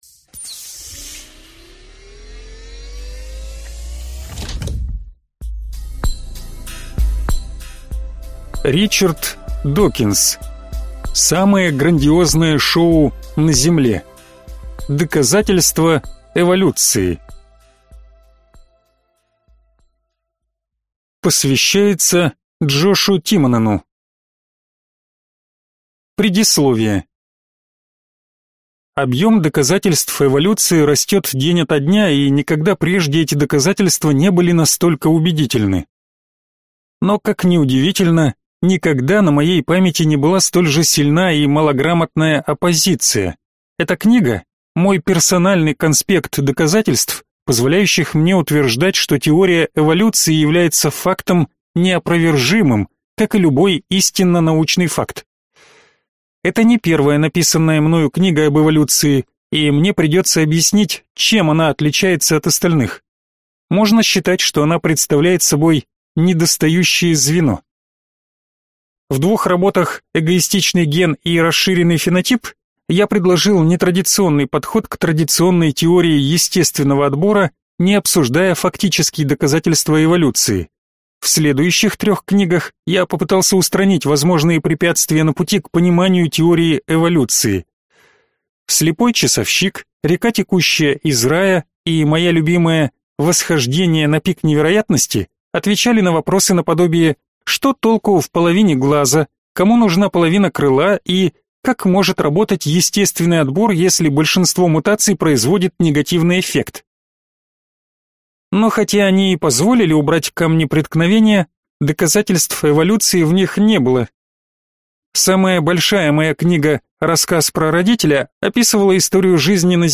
Аудиокнига Самое грандиозное шоу на Земле: доказательства эволюции | Библиотека аудиокниг